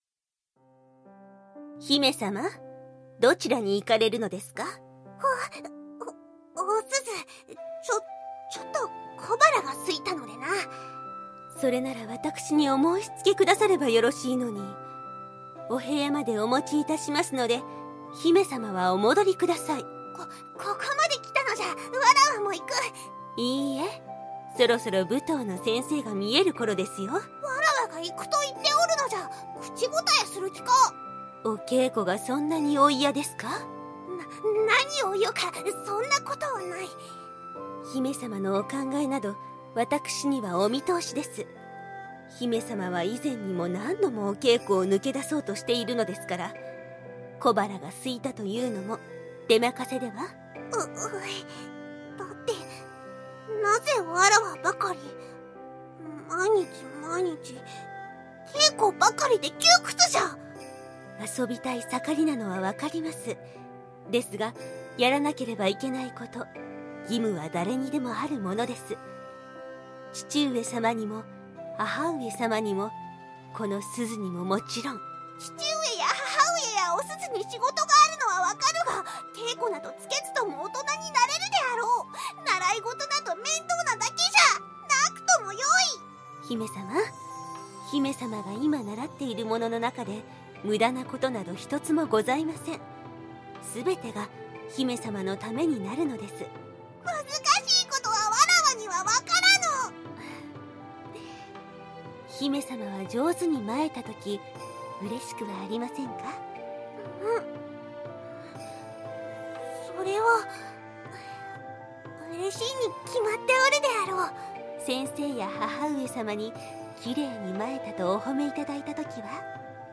武家の小さなお姫様と、その教育係の掛け合いです。
少しわがままな感じが良く出ていたと思います！！
窮屈な暮らしの中でも、おすずには甘えて等身大のわがままを言える姫様を演じてみたかったので、そう言っていただけてうれしいです♪